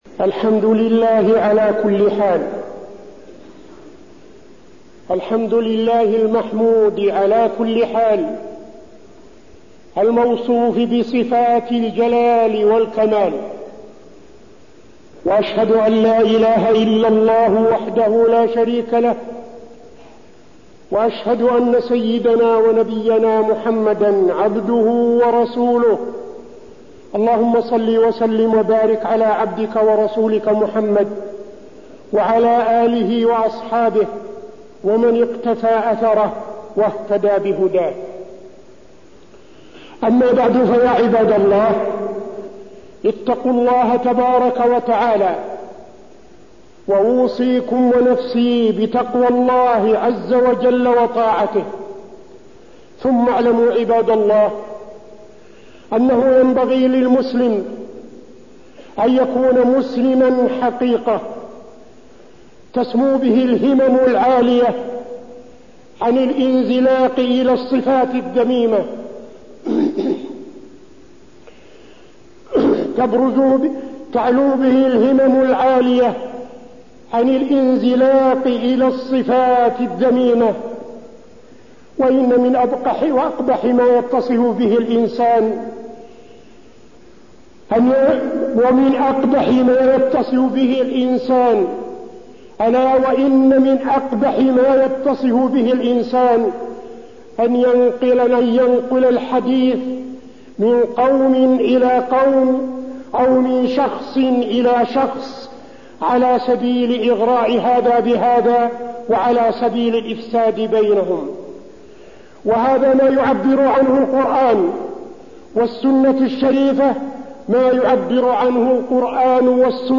تاريخ النشر ٢٨ جمادى الآخرة ١٤٠٤ هـ المكان: المسجد النبوي الشيخ: فضيلة الشيخ عبدالعزيز بن صالح فضيلة الشيخ عبدالعزيز بن صالح النميمة